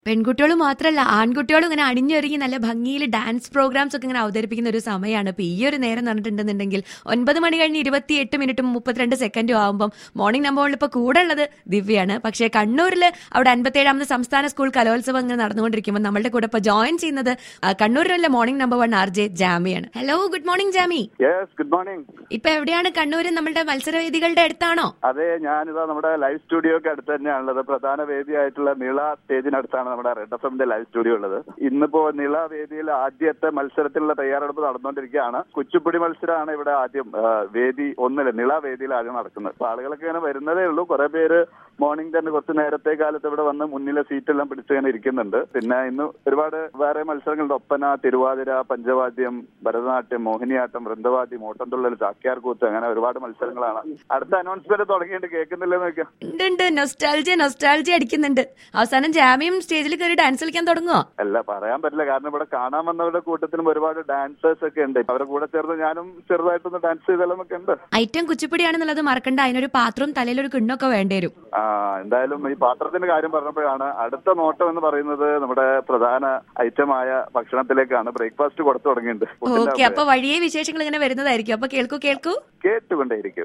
KANNUR KALOLSAVAM - OB